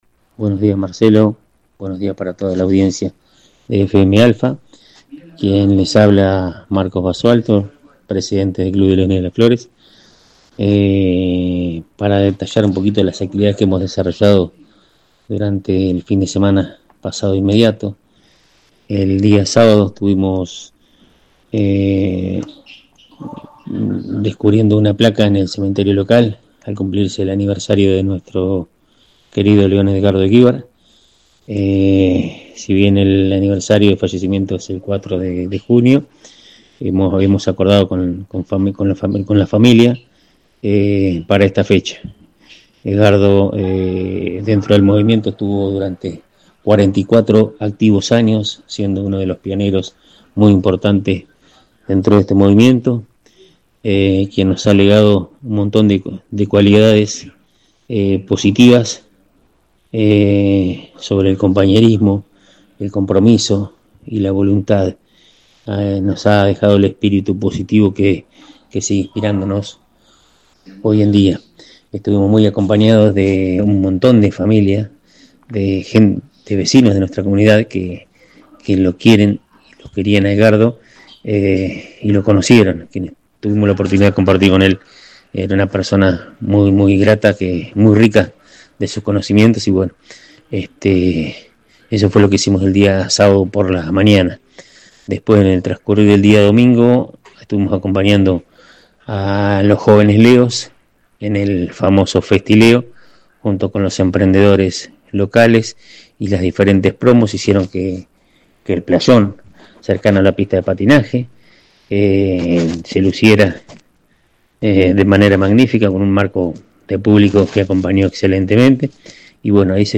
Durante la conversación con nuestra radio